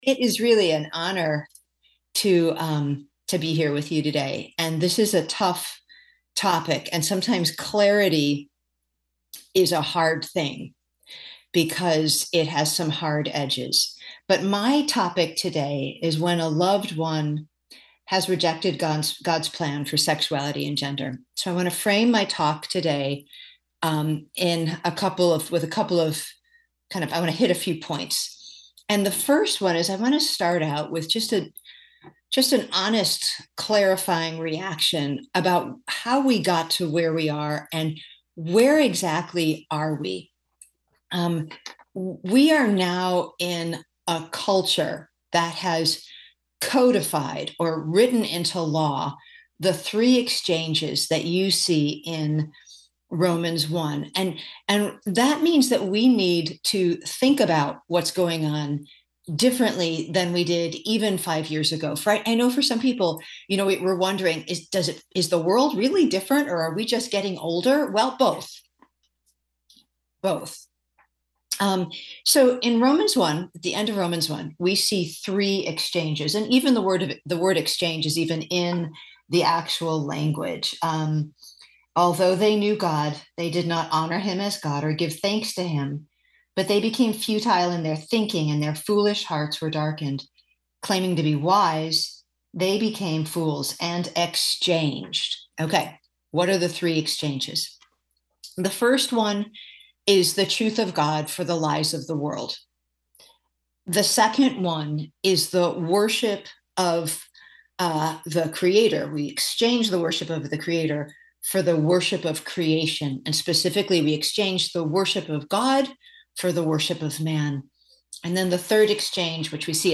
When a Loved One Rejects God’s Design | True Woman '22 | Events | Revive Our Hearts